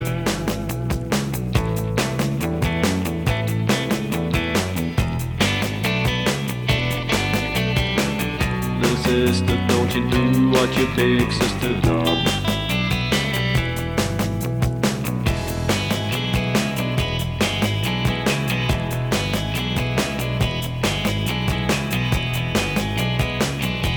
Minus Guitars Pop (1960s) 2:34 Buy £1.50